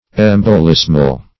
Search Result for " embolismal" : The Collaborative International Dictionary of English v.0.48: Embolismal \Em`bo*lis"mal\, a. Pertaining to embolism; intercalary; as, embolismal months.
embolismal.mp3